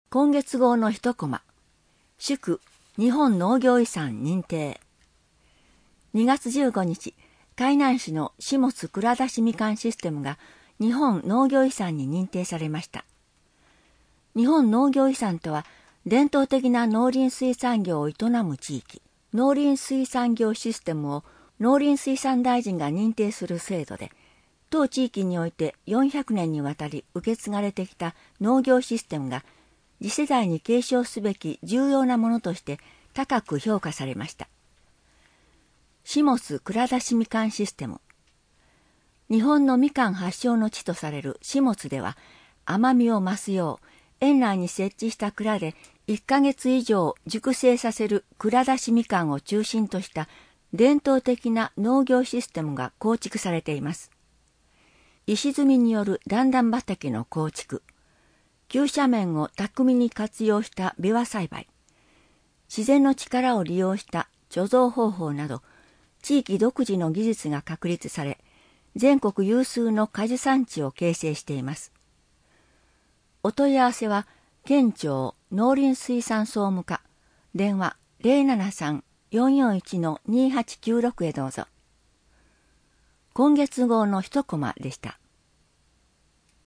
「声の県民の友」はボランティア団体「和歌山グループ声」の皆さんのご協力により作成されています。